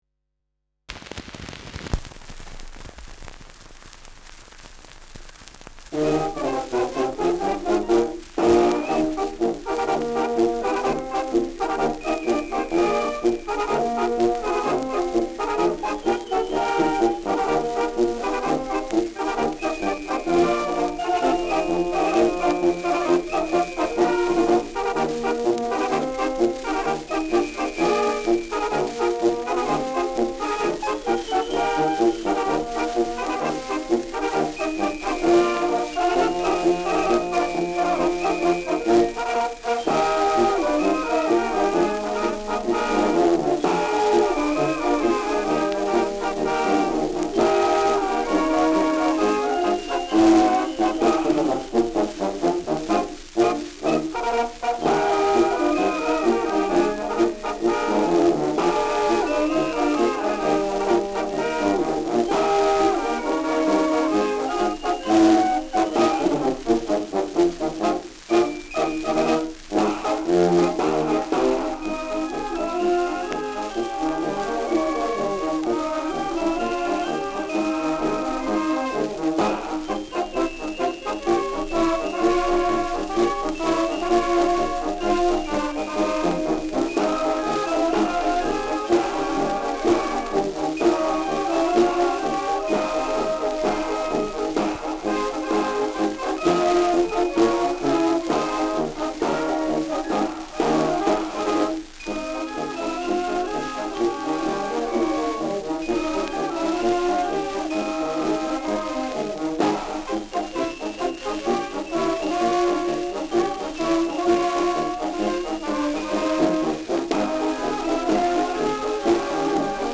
Schellackplatte
Tonrille: Kratzer 1 / 12 Uhr Leicht
präsentes Rauschen
Kapelle Peuppus, München (Interpretation)